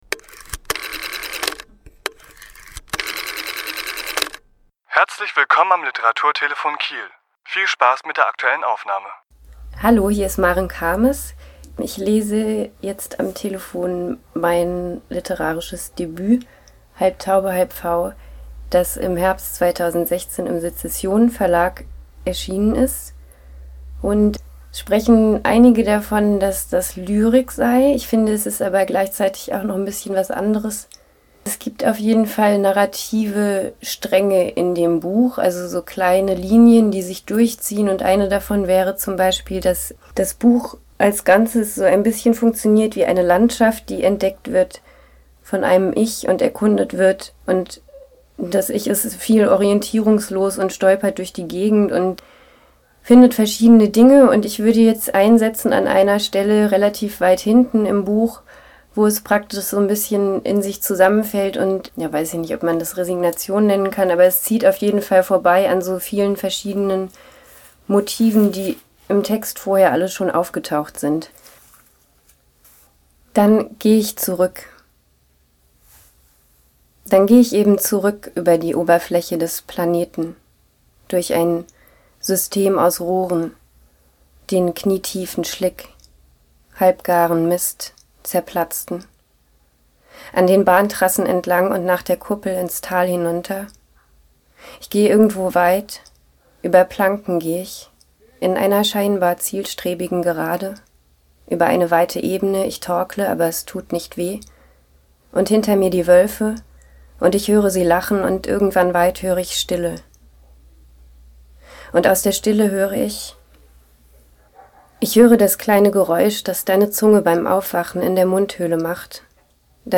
Autor*innen lesen aus ihren Werken
Die Aufnahme entstand bei einer Lesung im Rahmen der Leselounge im Literaturhaus Schleswig-Holstein am 19.4.2017.